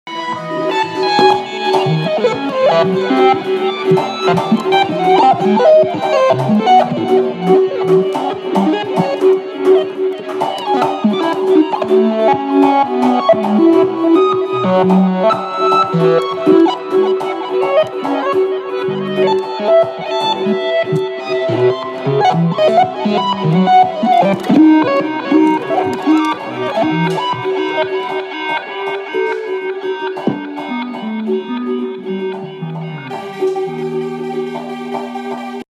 Bugs in my head
Bugs-in-my-head.mp3